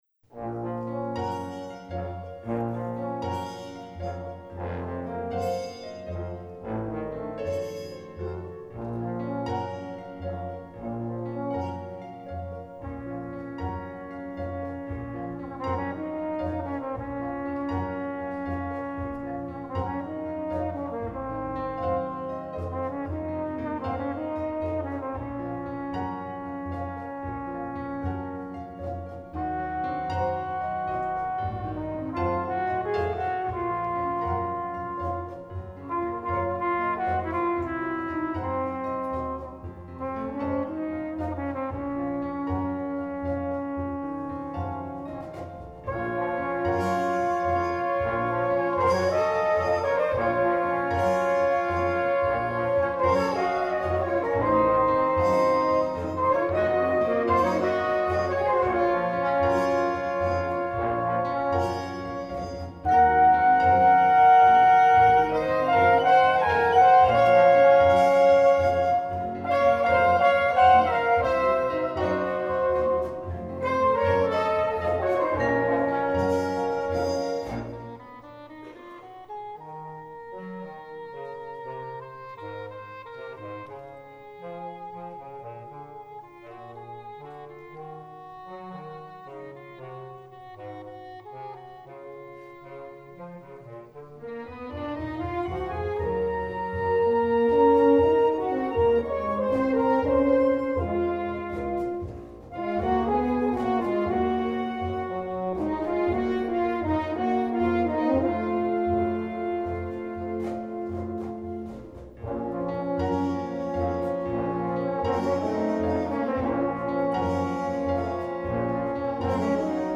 Performing Lonely Beauty w/ the Army Field Band.
The first piece was a slow rhumba entitled Lonely Beauty. I wrote it for brass quintet and drums in 2011, making it one of my first original pieces for brass.
Lonely Beauty, recorded live on September 24, 2013.